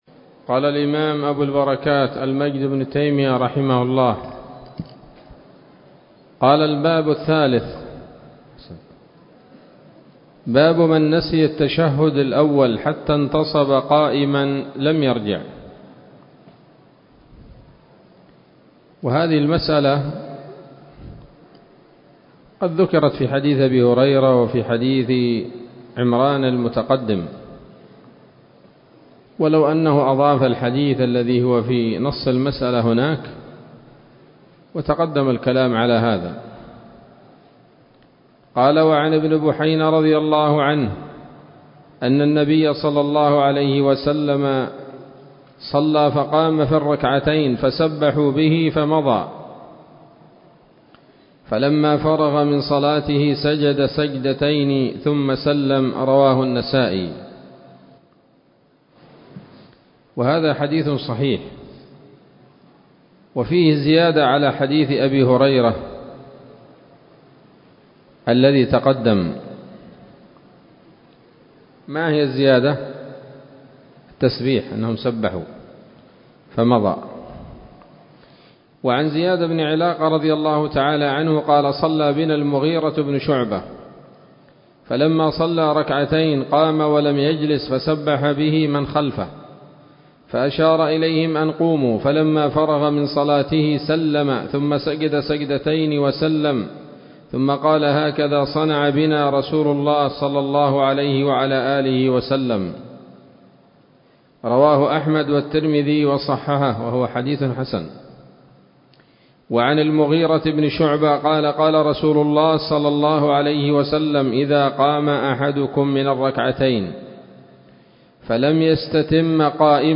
الدرس الثامن من ‌‌‌‌أبواب سجود السهو من نيل الأوطار